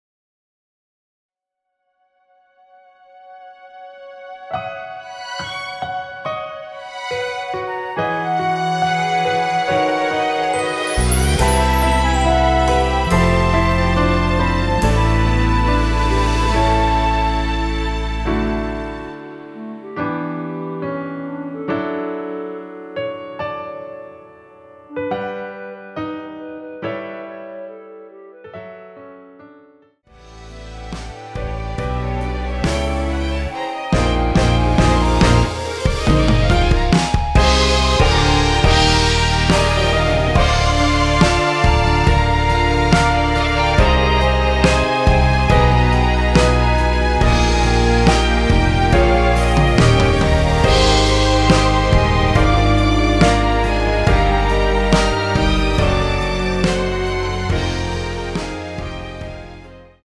원키에서(+4)올린 멜로디 포함된 MR 입니다.(미리듣기 참조)
앞부분30초, 뒷부분30초씩 편집해서 올려 드리고 있습니다.
중간에 음이 끈어지고 다시 나오는 이유는